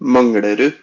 Pronunciation of "Manglerud" in Norwegian